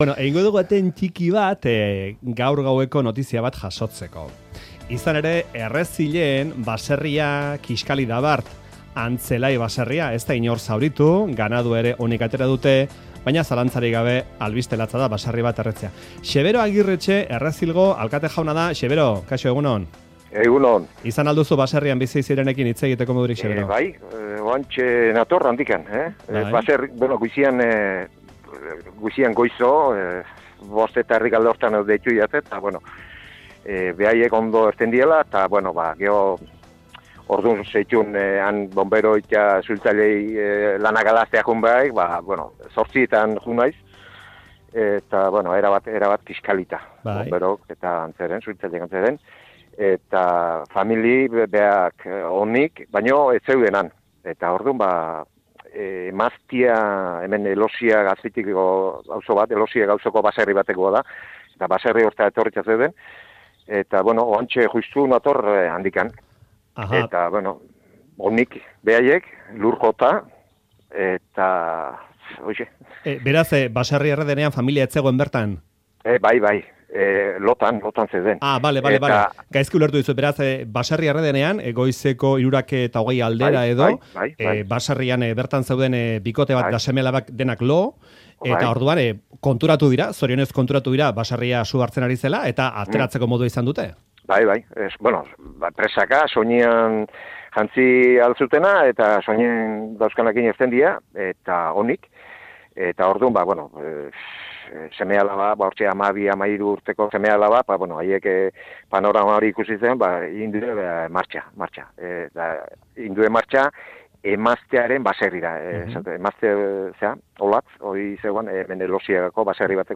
Audioa: Baserri bat guztiz kiskali da bart gauean Errezilen. Xebero Agirretxe da herriko alkatea eta ‘Faktoira’n eman dizkigu azken ordukoen berri.